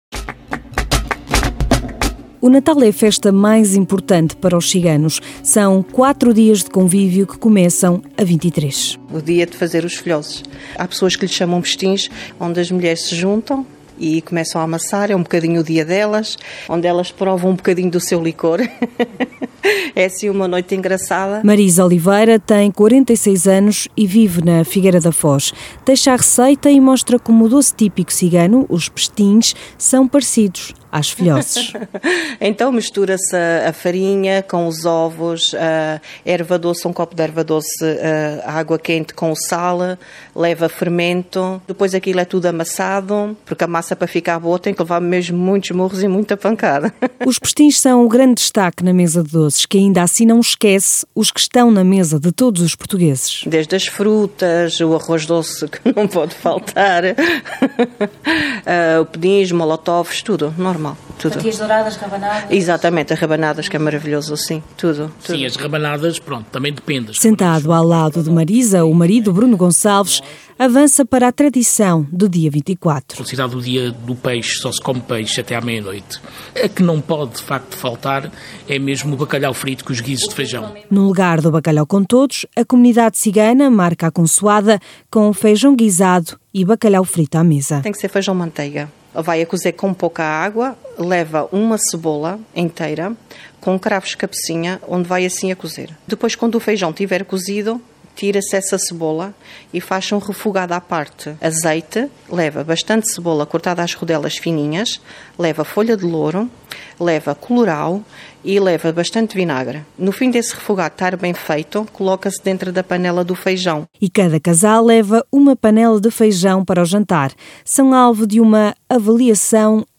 Todas as semanas, uma entrevista para ajudar a entender as opções de política económica e o caminho que as empresas vão abrindo na conquista de mercados, nacionais e internacionais.